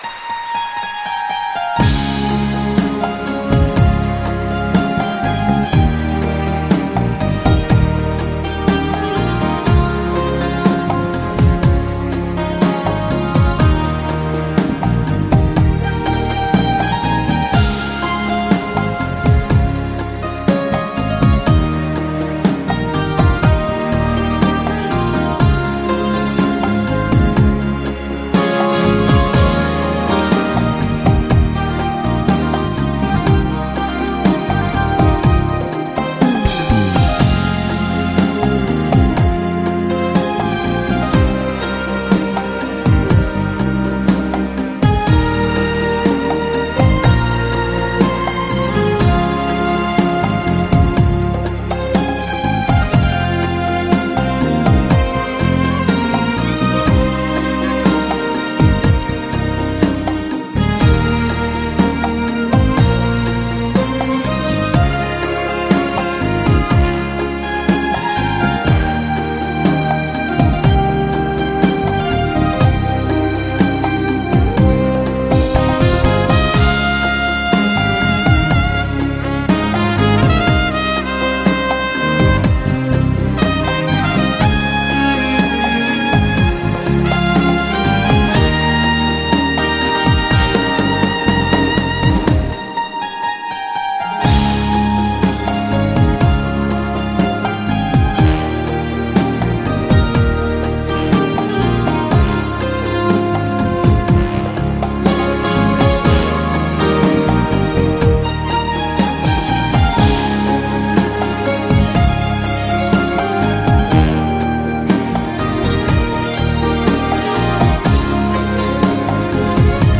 充滿，令人鼓舞